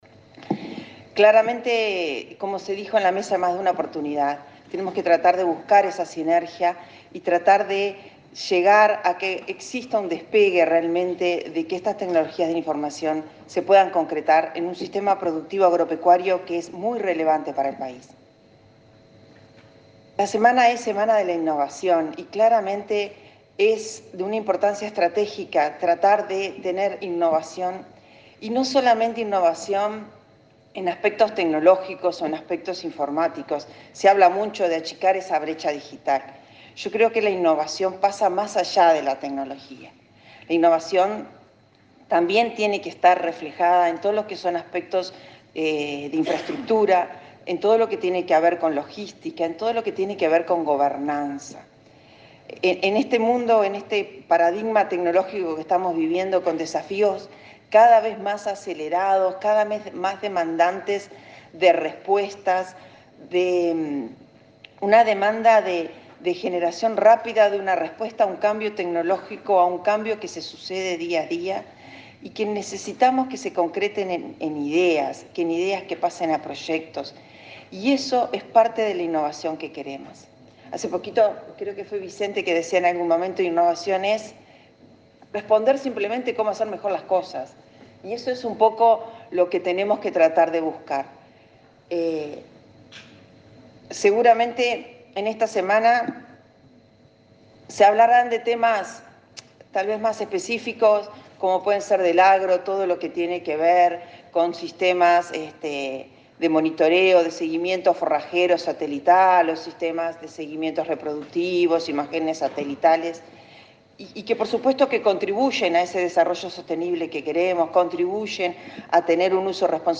La subsecretaria de Industria, Olga Otegui, remarcó que achicar la brecha entre tecnología, informática y telecomunicaciones en la producción agropecuaria es clave en la discusión de la Semana de la Innovación en América Latina cuya apertura se realizó este lunes en Montevideo.